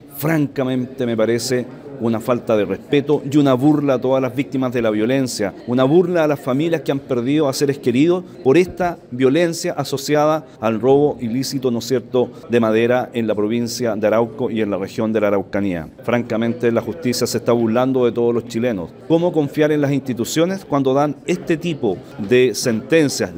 Esta sentencia generó críticas en parlamentarios, siendo el diputado DC, Eric Aedo, calificó como una falta de respeto y una burla la decisión del tribunal.
cuna-aedo-madera.mp3